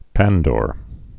(păndôr)